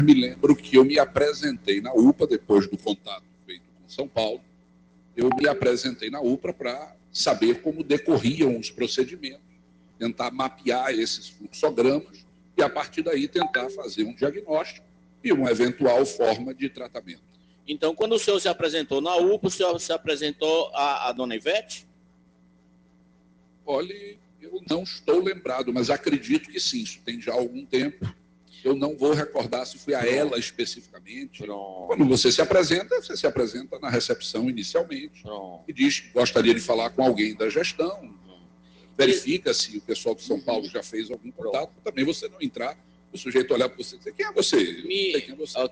O secretário foi desmoralizado no plenário da Câmara ao mentir no depoimento da CPI nesta terça-feira (12).
Ouçam os áudios no momento em que Dr. Marcelo teria mentido na CPI e foi duramente advertido pelos edis.